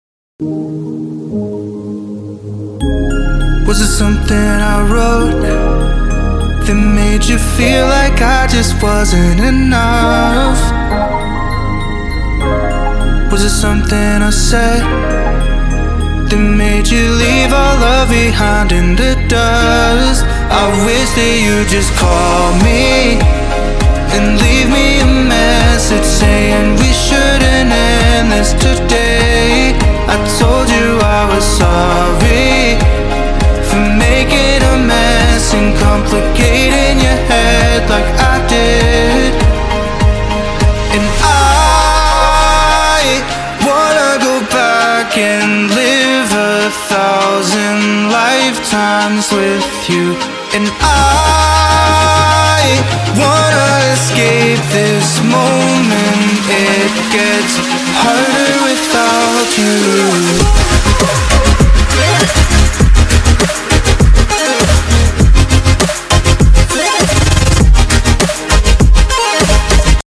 每个无伴奏合唱曲目都有干式和湿式版本，还包括和声。